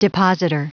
Prononciation du mot depositor en anglais (fichier audio)
Prononciation du mot : depositor